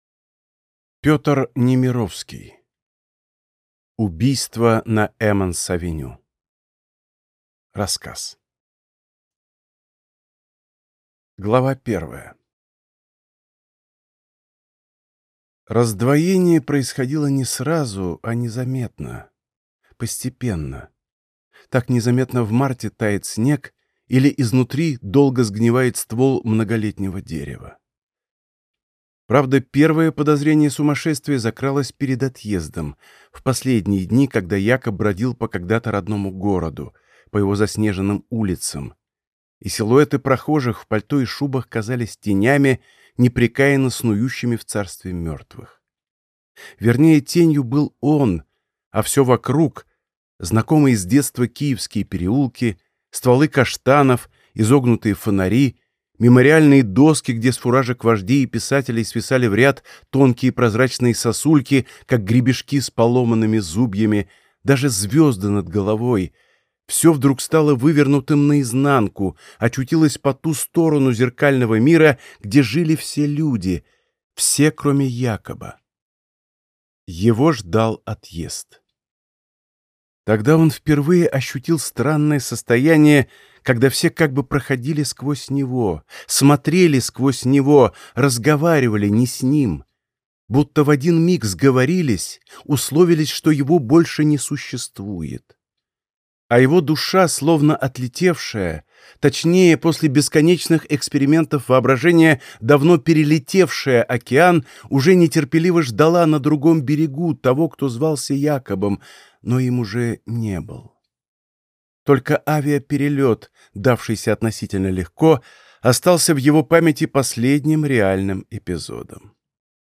Аудиокнига Убийство на Эммонс авеню | Библиотека аудиокниг